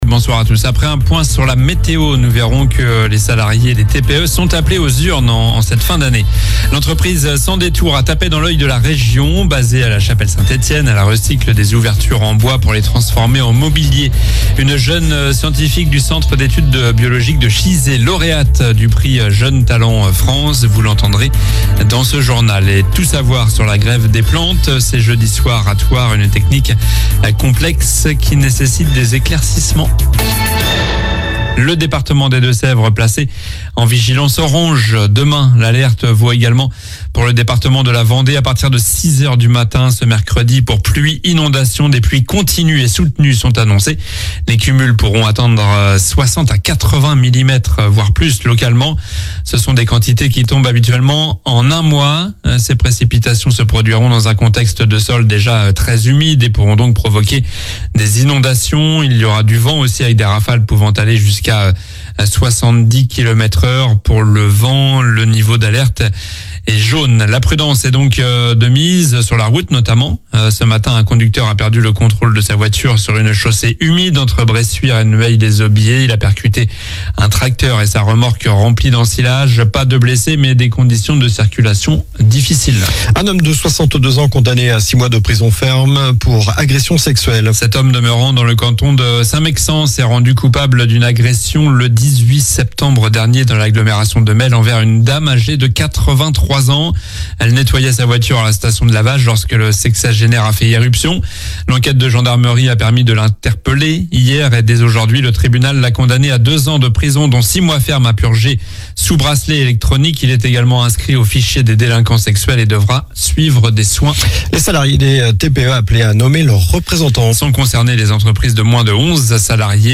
Journal du mardi 08 octobre (soir)